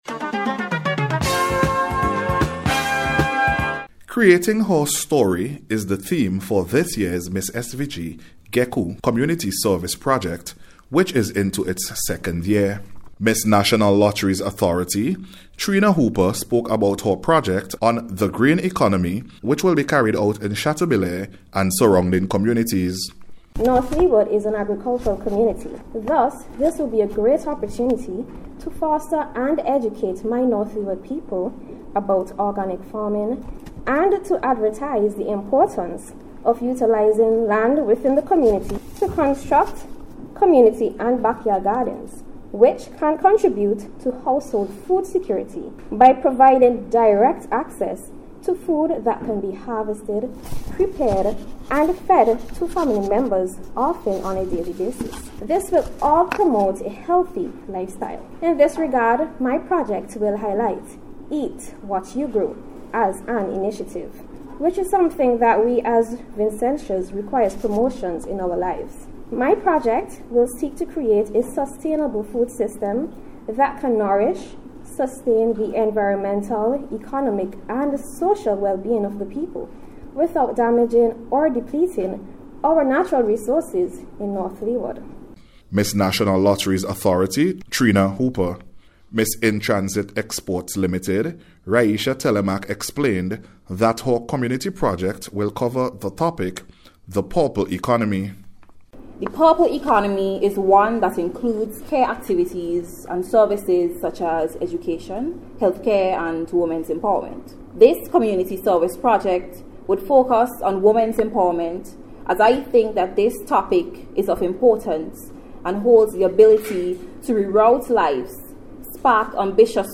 COMMUNITY-SERVICES-PROJECT-MISS-SVG-REPORT.mp3